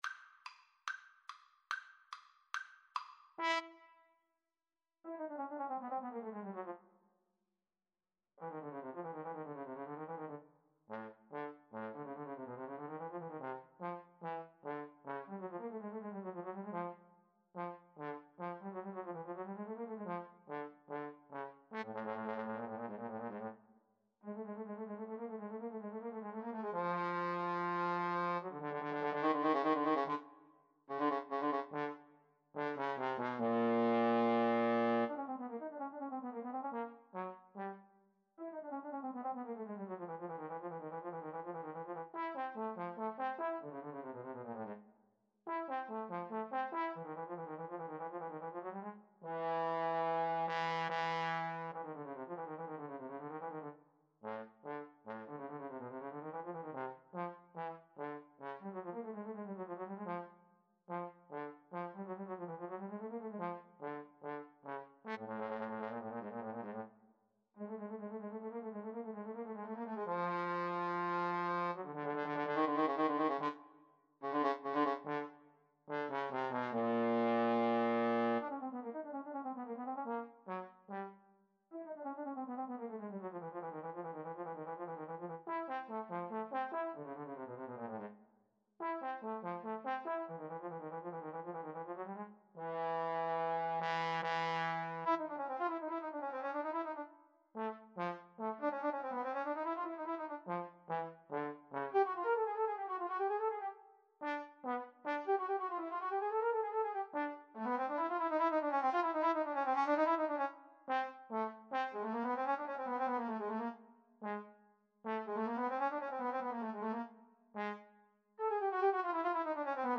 Vivace = 144 (View more music marked Vivace)
A minor (Sounding Pitch) (View more A minor Music for Trombone Duet )
Classical (View more Classical Trombone Duet Music)